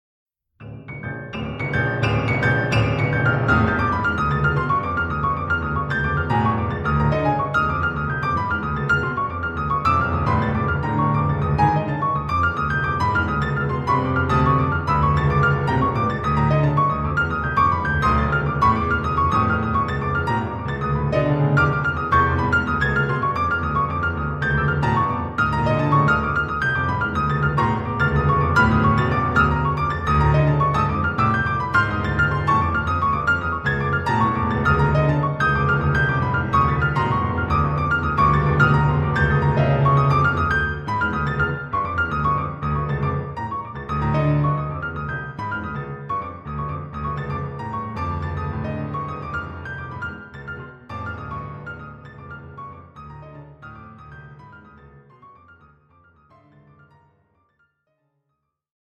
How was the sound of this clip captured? North Melbourne Elm Street Hall